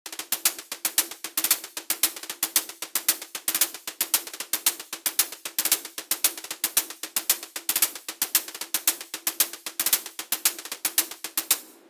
Not so much an oddball but I’m on a hi-hat quest atm trying to make some natural sounding hats with the help of velocity mod.